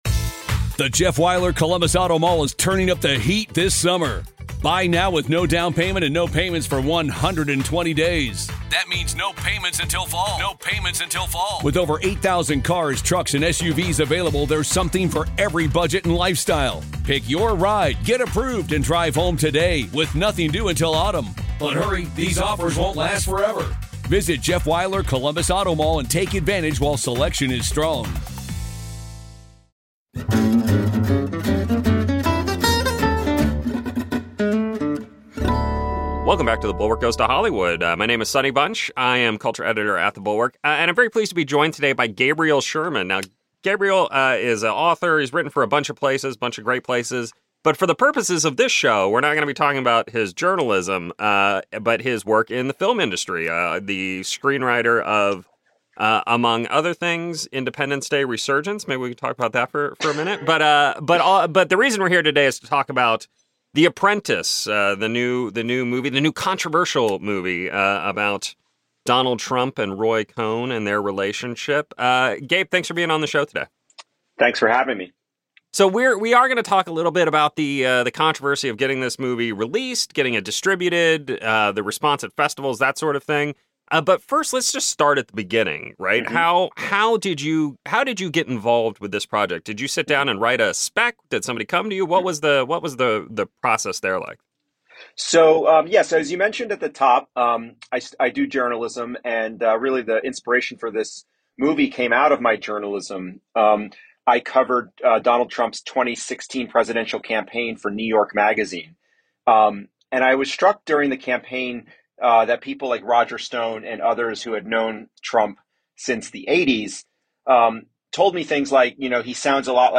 I’m joined by Gabriel Sherman, the writer of The Apprentice, on this week’s episode. Sebastian Stan plays Donald Trump in this movie in theaters now about the future president’s relationship with noted legal fixer and possible evil supervillain Roy Cohn (Jeremy Strong).